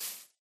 Sound / Minecraft / step / grass1.ogg
grass1.ogg